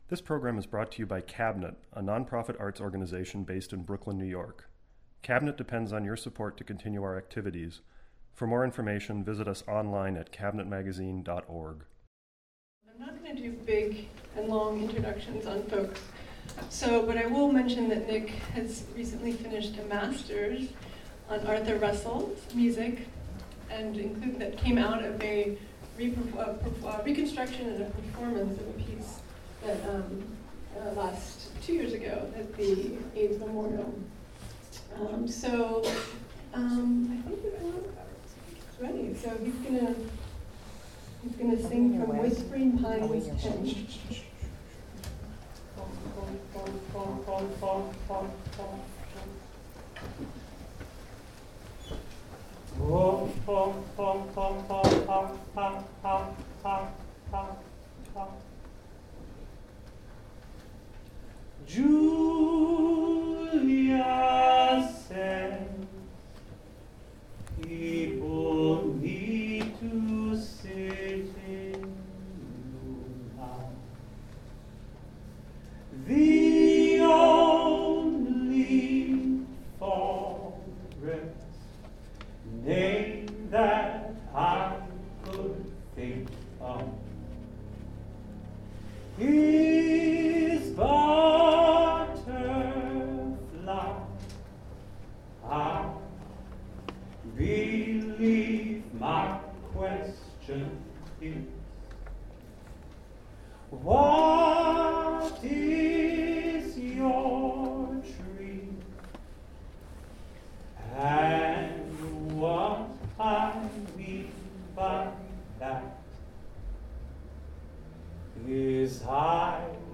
Date: Wednesday, 19 March 2025, 7–9 pm Location: Cabinet, 300 Nevins Street, Brooklyn (map and directions here) FREE.